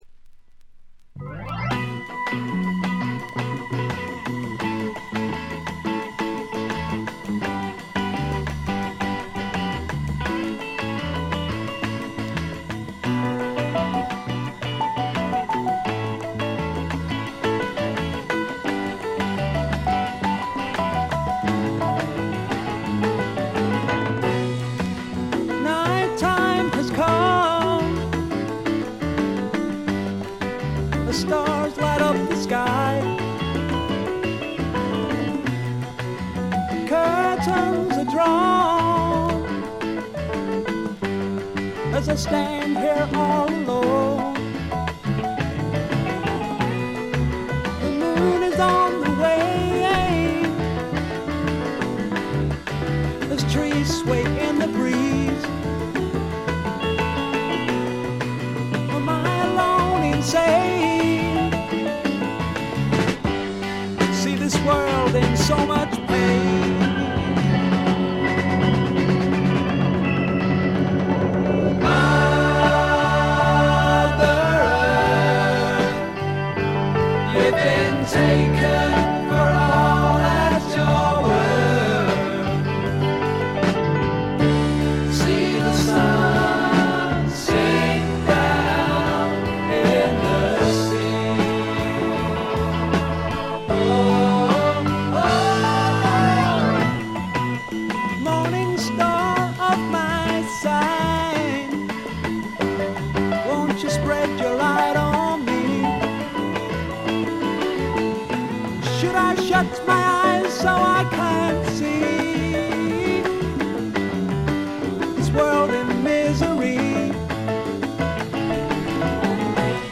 静音部で軽微なチリプチ少々。散発的なプツ音3箇所ほど。
いわずと知れた英国フォークロックの名盤です。
アメリカでは絶対に生まれ得ない、陰り、くすみ、ほのかなプログレ風味といった陰影に富んだ英国臭がふんだんに味わえます。
哀愁の英国フォークロック基本盤。
試聴曲は現品からの取り込み音源です。
Recorded at Olympic Sound Studios and Morgan, April 1971.